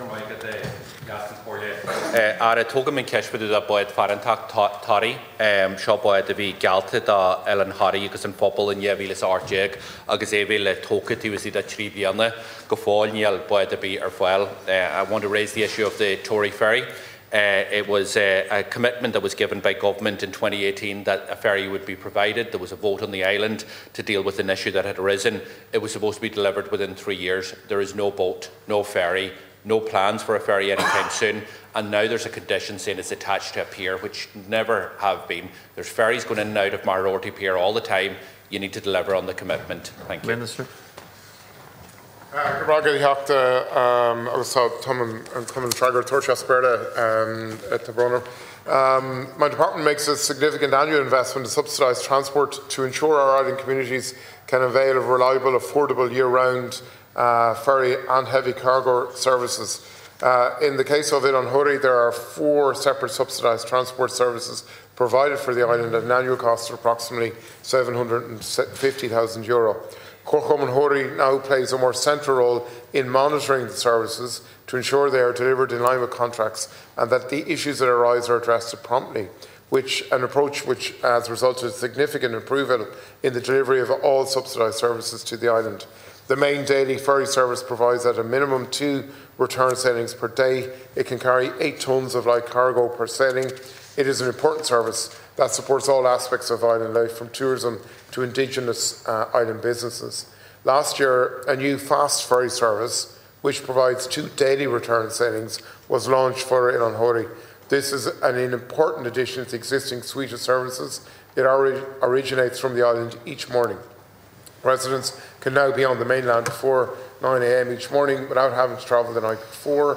The Dáil heard yesterday that it was supposed to be delivered within three years.
Donegal Deputy Pearse Doherty was addressing the Minister for Rural and Community Development, when he said that the proposal is yet to even reach the planning or design stages.